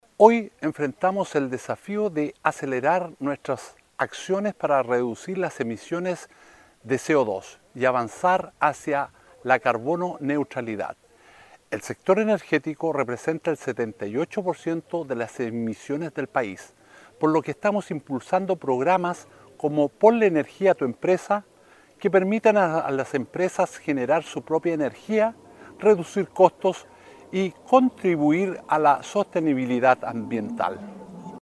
El seremi de Energía, Jorge Cáceres, destacó la importancia de fomentar este tipo de iniciativas.